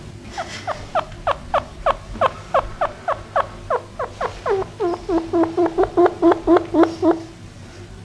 Звуки шиншиллы: какие звуки издает этот зверек?